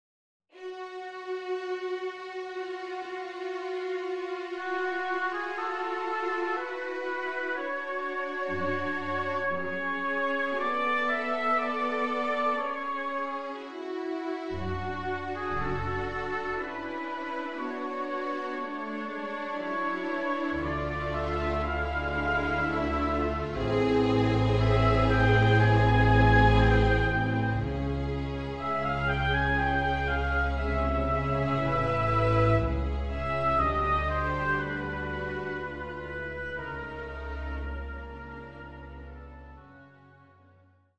II Poco lento
A short excerpt of the opening of the slow movement